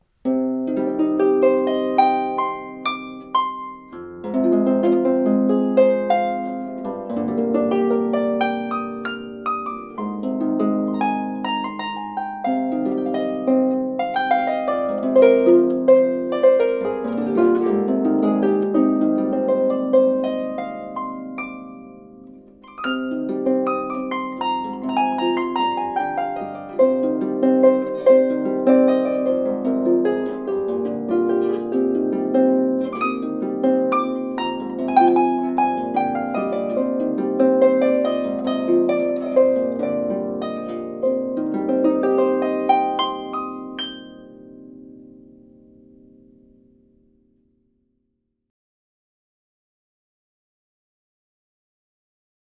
Kleine keltische Harfe, 31 Saiten
Tonumfang F bis a´´´
Neben ihrem handlichen Format besticht diese Harfe durch einen für Instrumente dieser Größe ungewöhnlich vollen Klang.